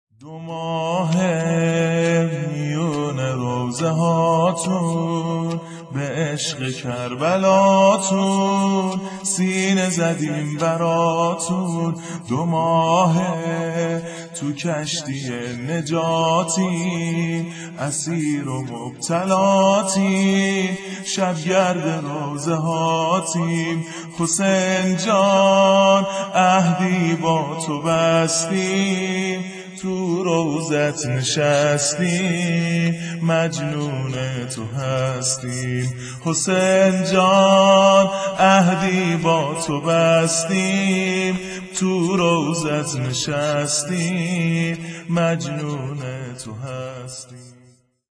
حسین الشهید - - -- - -شور-زمینه